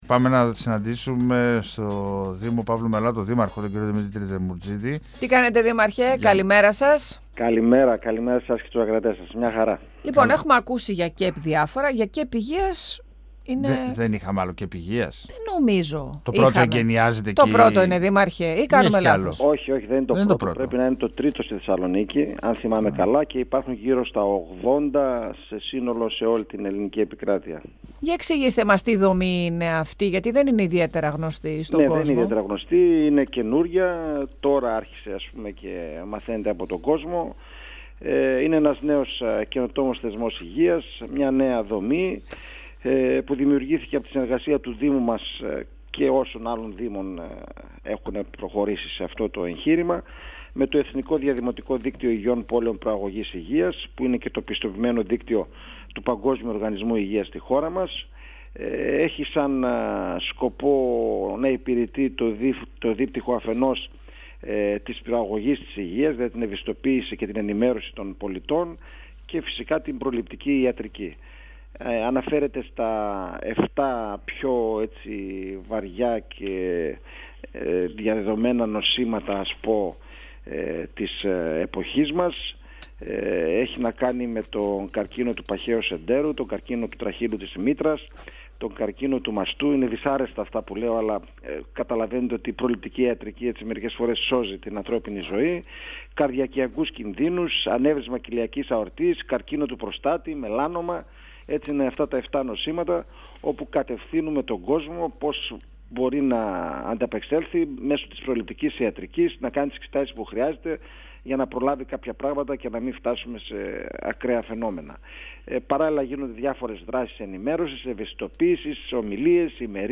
Ο δήμαρχος Παύλου Μελά Δημήτρης Δεμουρτζίδης, στον 102FM του Ρ.Σ.Μ. της ΕΡΤ3